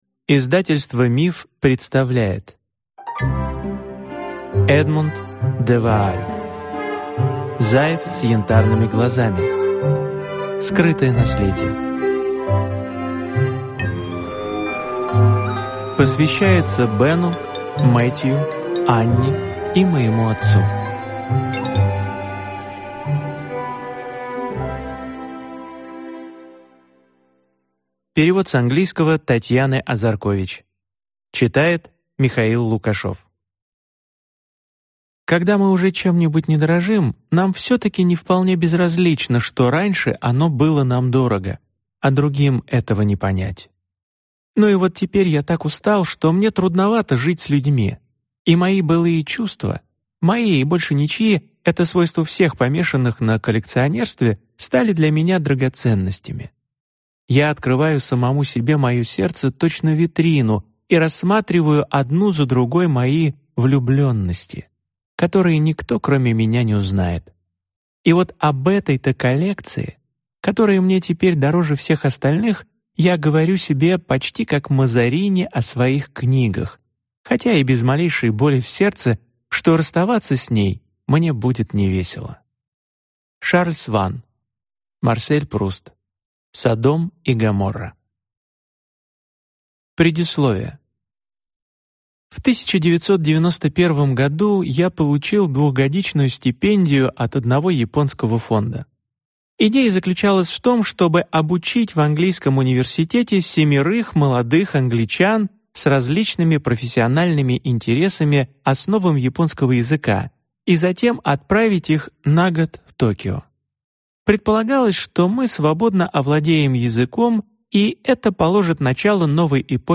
Аудиокнига Заяц с янтарными глазами. Скрытое наследие | Библиотека аудиокниг